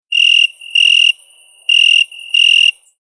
Awkward Silence Sound Effect
A high-quality close recording captures a single cricket chirp. Creators use this sound effect to emphasize awkward silence, failed jokes, or uncomfortable pauses.
Awkward-silence-sound-effect.mp3